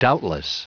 Prononciation du mot doubtless en anglais (fichier audio)
Prononciation du mot : doubtless